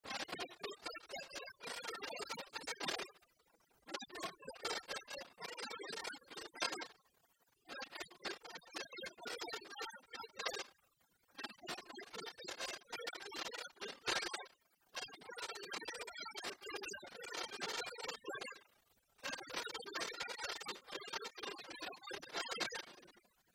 Résumé instrumental
danse-jeu : trompeuse
Pièce musicale inédite